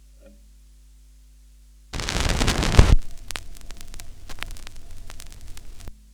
Je vous offre volontiers mes enregistrements de craquements de vinyle "bien vécu" que j'ai réalisés sur une Thorens TD 128 MK II... icon_mdr.gif
Les fichiers audio sont au format Aiff Stéréo 16 bit 44'100 Hz, perso je les assemble en réalisant des fondus croisés...
vinyle 2
Craquements vinyl 2.aif